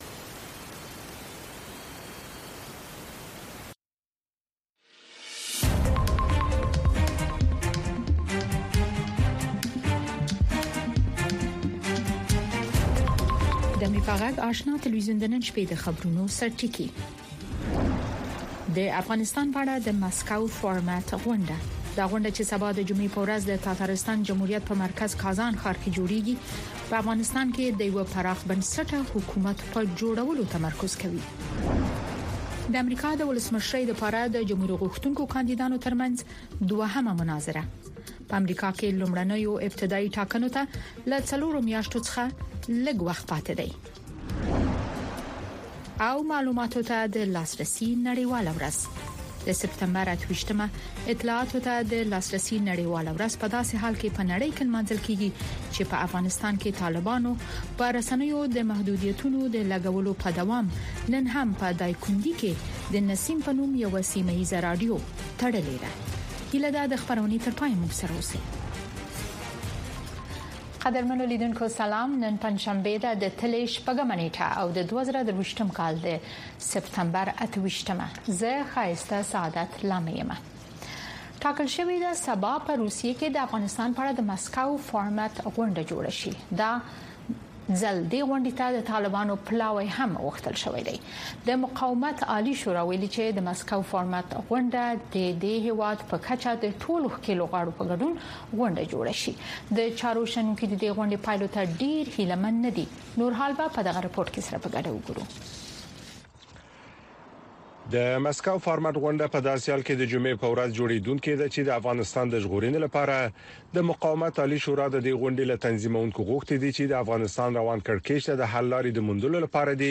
د اشنا خبري خپرونه